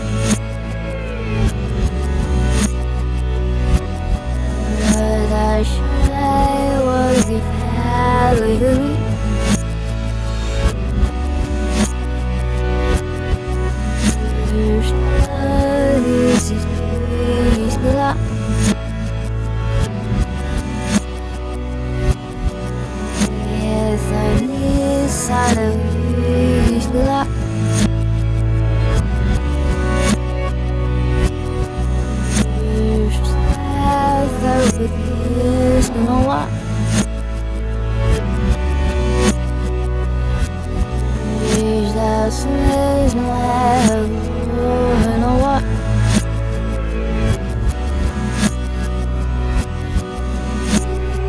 nouveau titre inversé.